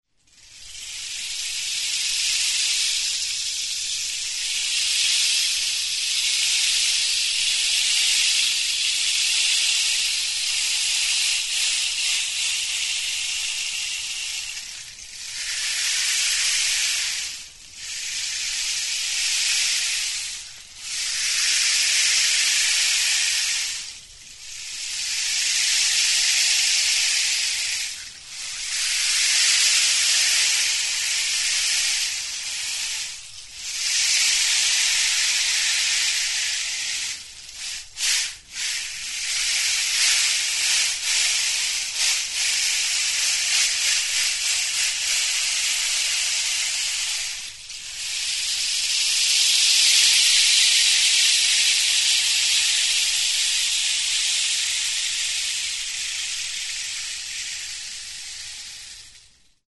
Instruments de musiquePALO DE LLUVIA
Idiophones -> Frappés -> Indirectement
Enregistré avec cet instrument de musique.
Bi muturrak itxita eta barnean hazi aleak dituen Cactus makila batekin egindako tutua da.